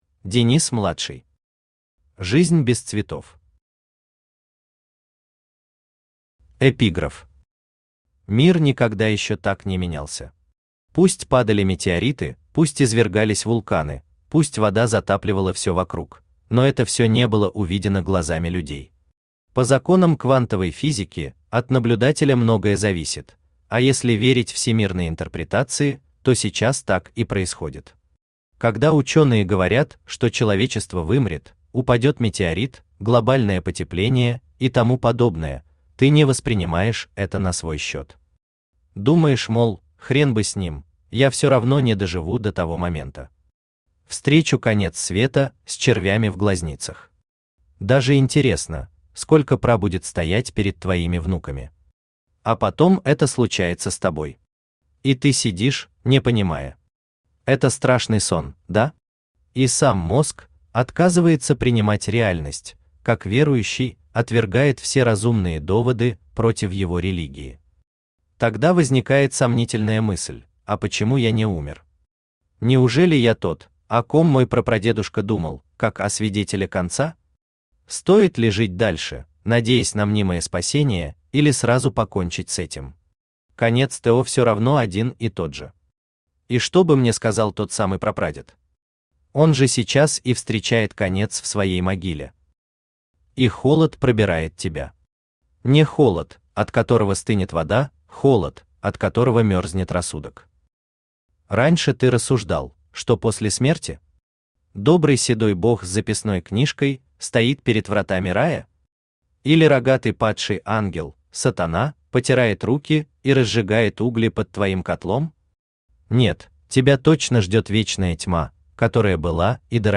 Аудиокнига Жизнь без цветов | Библиотека аудиокниг
Aудиокнига Жизнь без цветов Автор Денис Младший Читает аудиокнигу Авточтец ЛитРес.